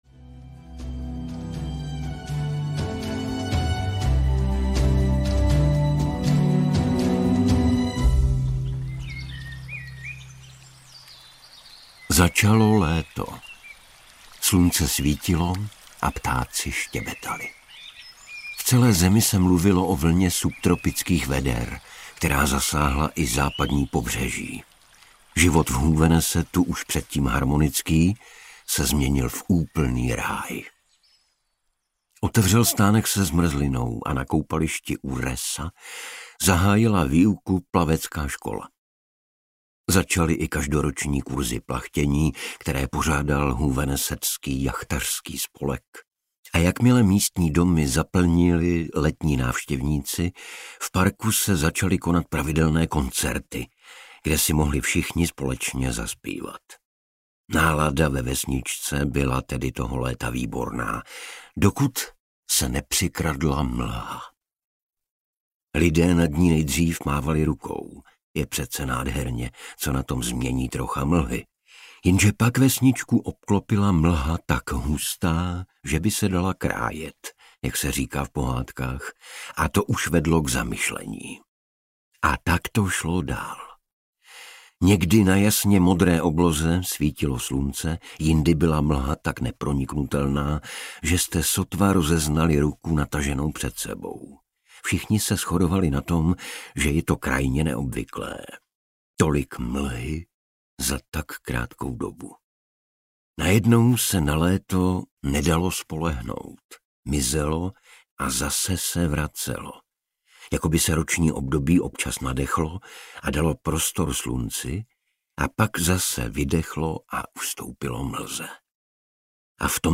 Mlha audiokniha
Ukázka z knihy
• InterpretIgor Bareš, Lucie Juřičková